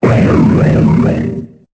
Cri de Tutankafer dans Pokémon Épée et Bouclier.